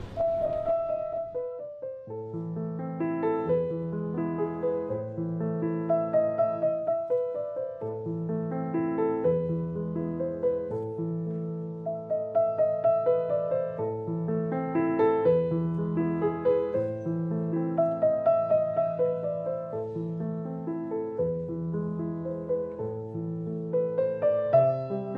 左手の伴奏部と同期し、音がクドくない程度に反響し、絶妙な音響を奏でます。